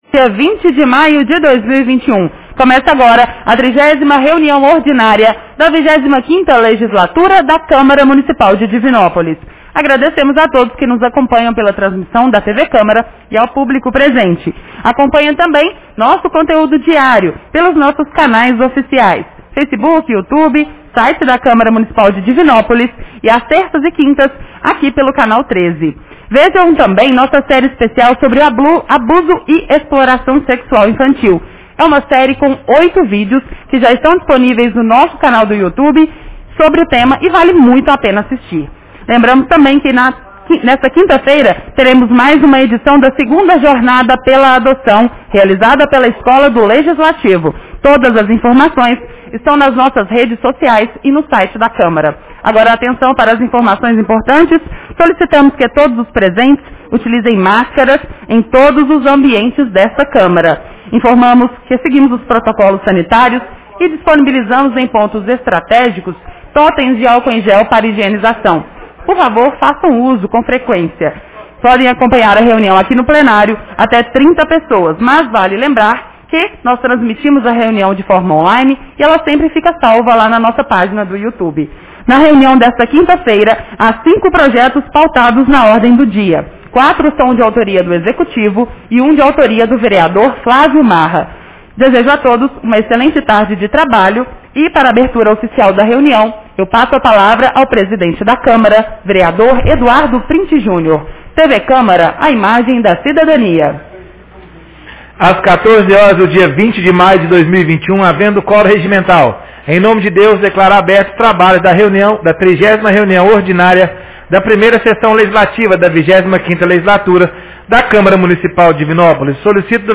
Reunião Ordinária 30 de 20 de maio 2021